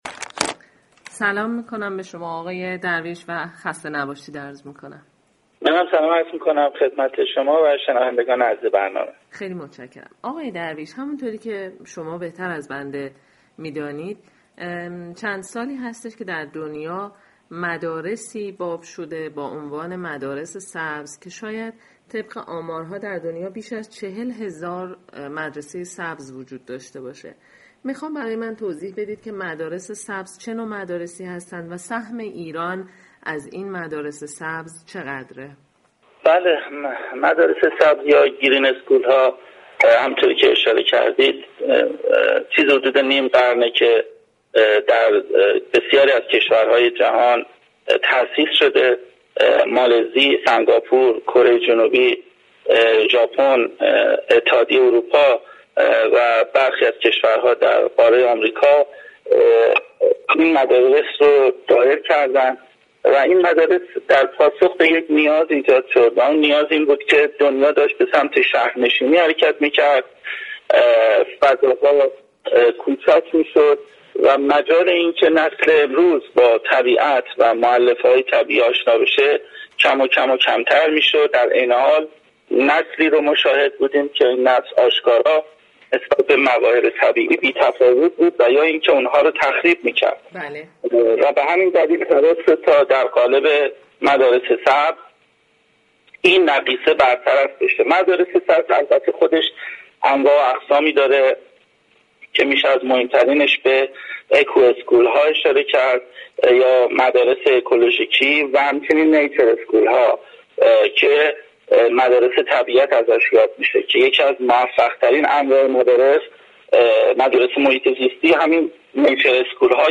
محمد درویش مدیركل مشاركت‌های مردمی سازمان محیط زیست در گفتگوی اختصاصی با سایت شبكه ی فرهنگ از مدارس طبیعت سخن گفت .